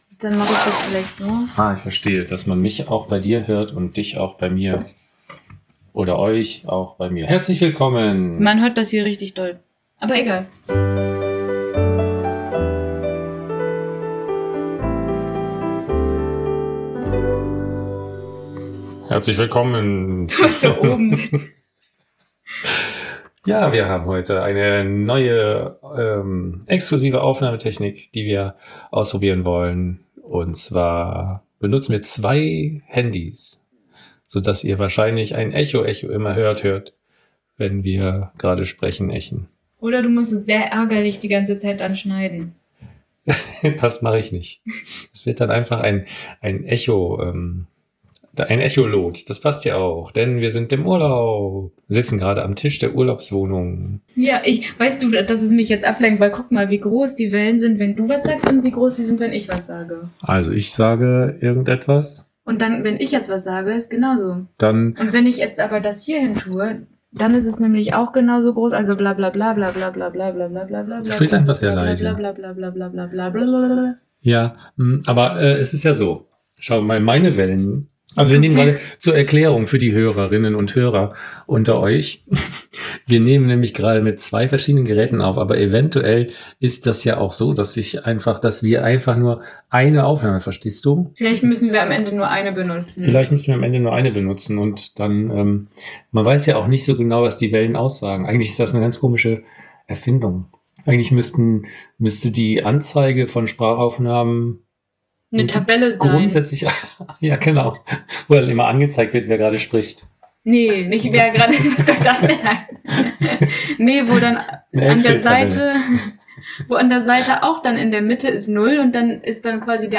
Wir bitten, die nicht ganz optimale Tonqualität zu entschuldigen: Wir haben mit dem Handy aufgenommen.